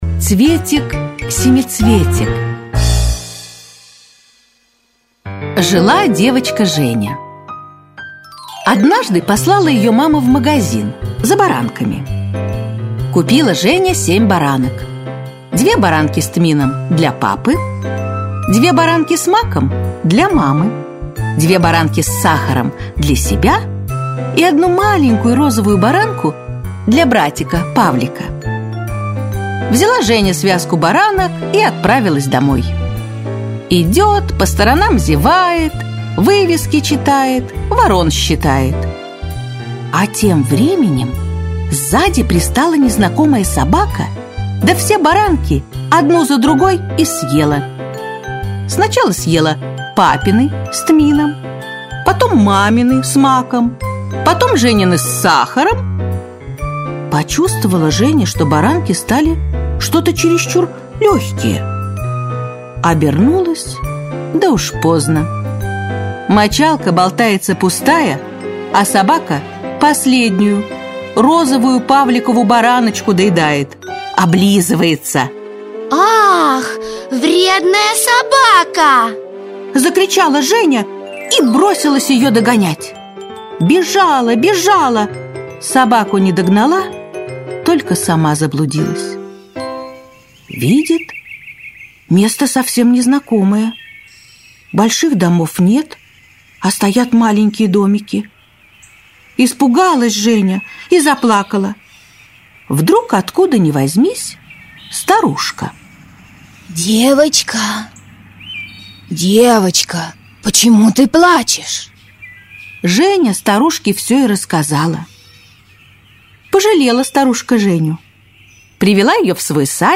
Аудиосказка Цветик-семицветик - Аудиосказки
Аудиосказка Цветик-семицветик для детей любого возраста в формате mp3 — слушать или скачать бесплатно и без регистрации.